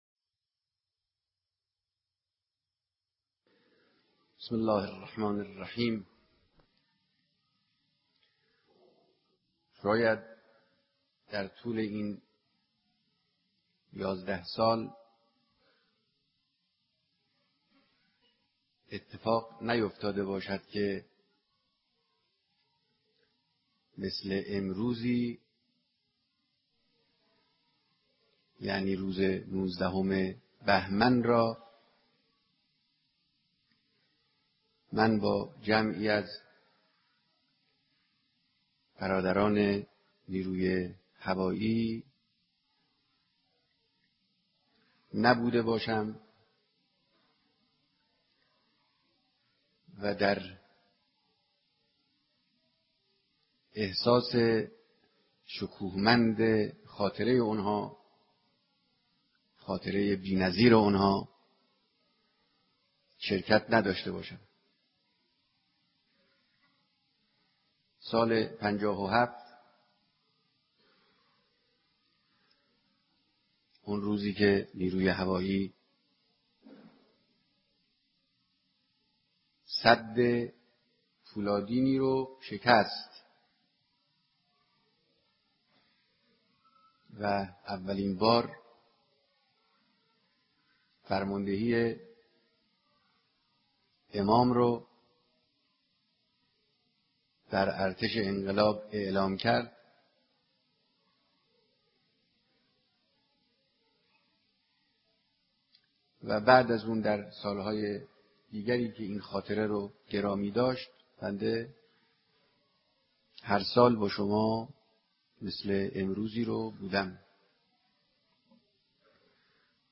صوت کامل بیانات
سخنرانی در دیدار با فرمانده و جمع کثیری از پرسنل نیروی هوایی ارتش جمهوری اسلامی ایران